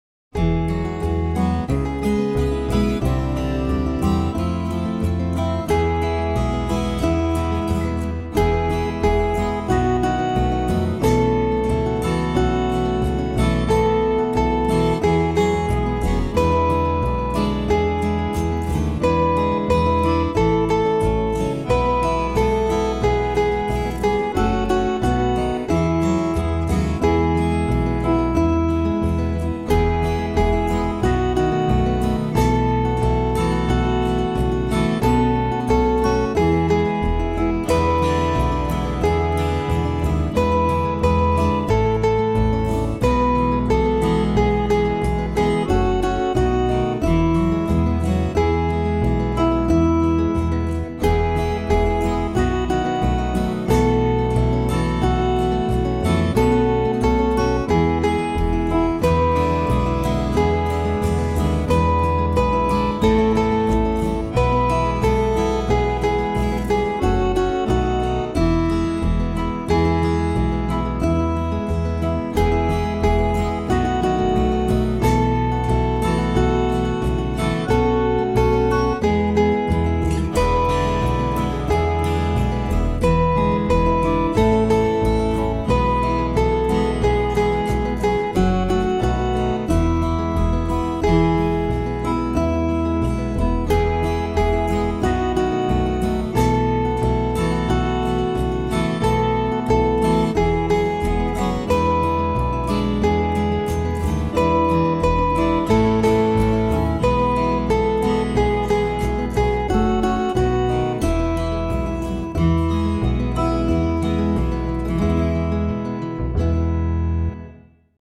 A hymn